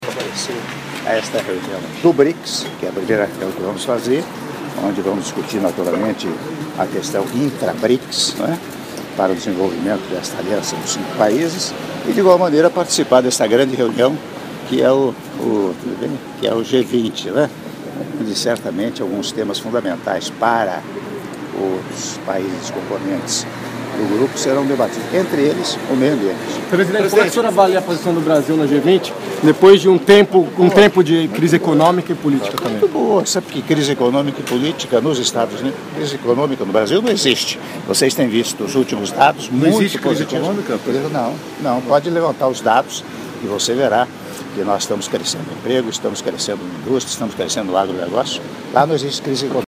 Áudio da Entrevista coletiva concedida pelo Presidente da República, Michel Temer, na chegada do Hotel Le Méridien - (0min49s) - Hamburgo/Alemanha